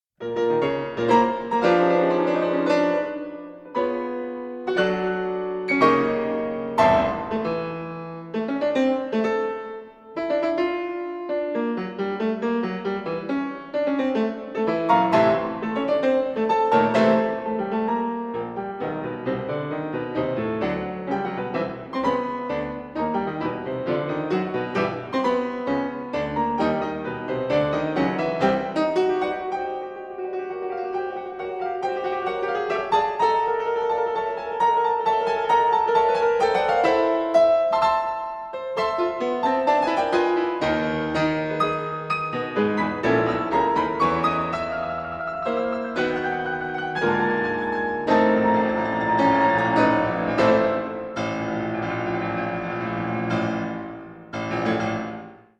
for two pianos